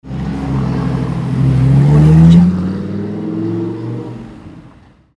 Download Free Lamborghini Sound Effects
Lamborghini